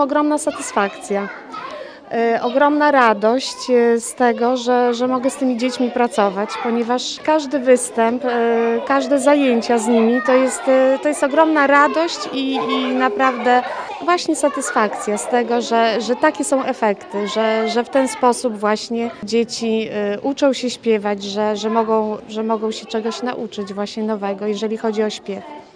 Wyjątkowe wydarzenie muzyczne odbyło się dziś (22.11) w Ełckim Centrum Kultury.
chór-4.mp3